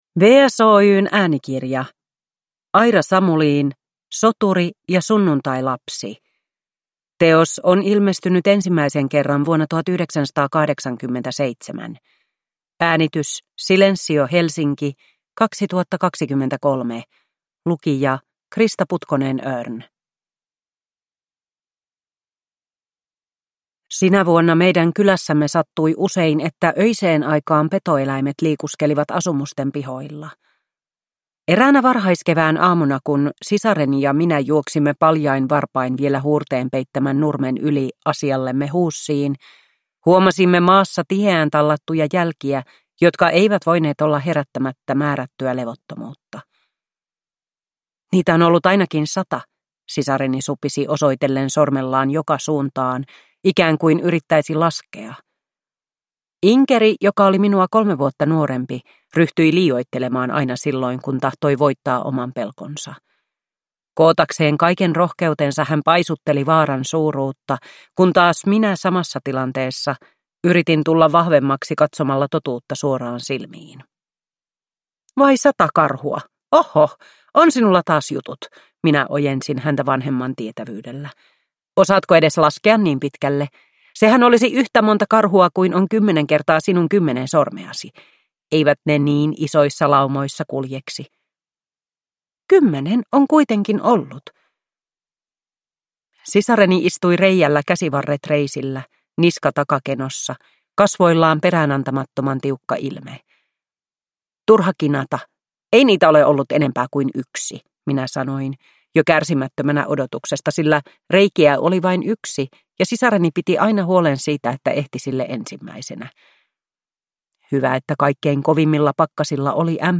Soturi ja sunnuntailapsi – Ljudbok